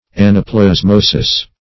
anaplasmosis.mp3